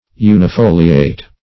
\U`ni*fol"li*ate\